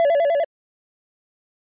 dialing02.mp3